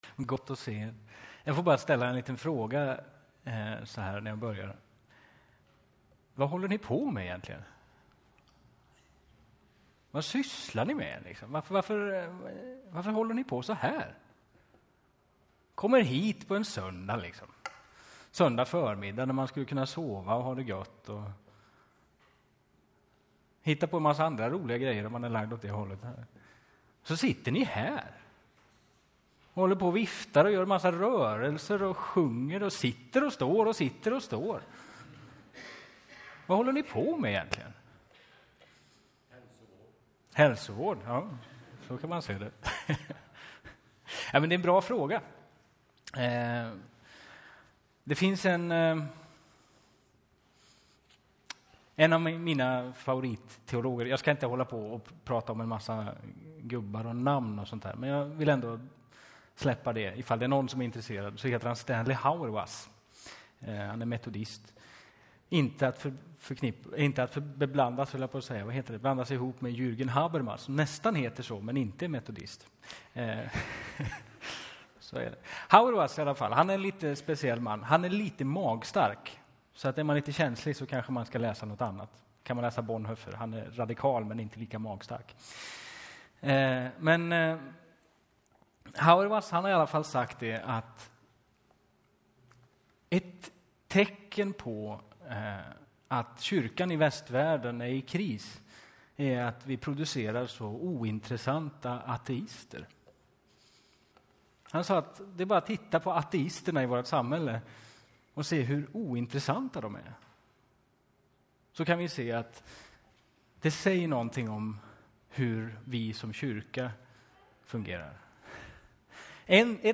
predikar.